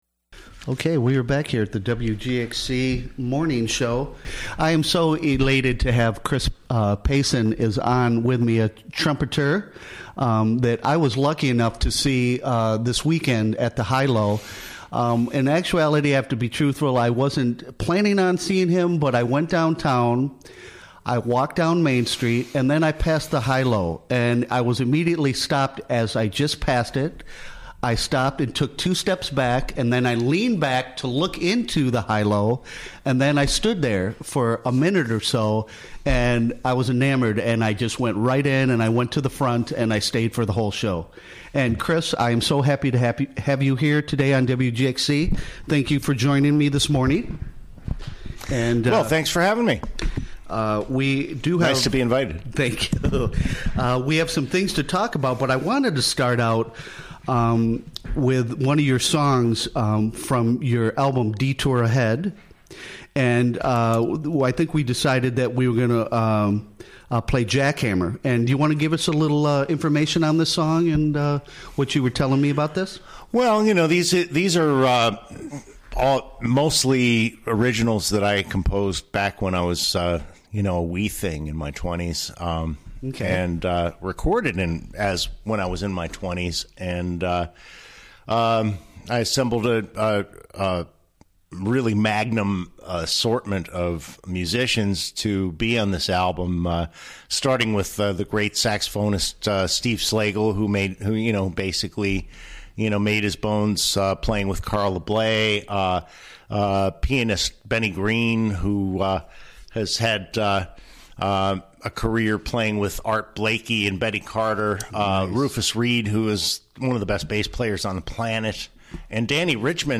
Recorded during the WGXC Morning Show of Tuesday, April 24, 2018.